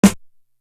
Big Twins Snare.wav